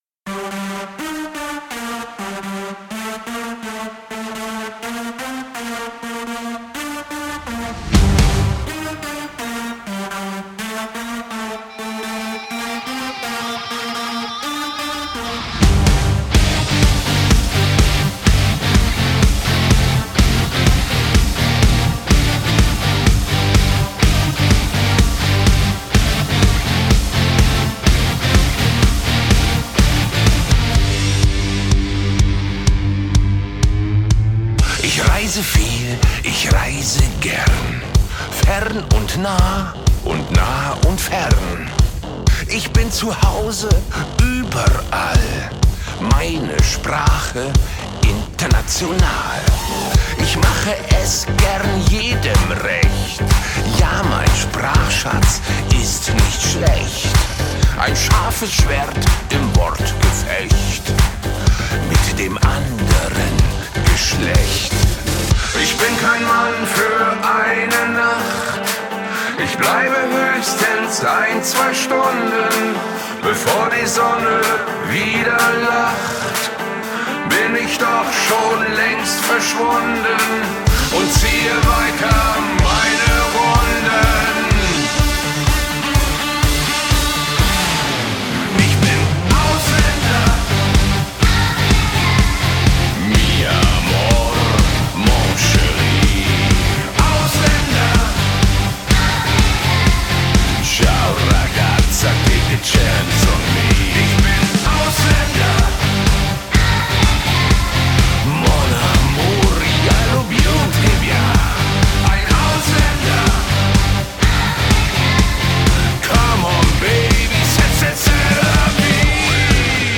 BPM62-125
Audio QualityCut From Video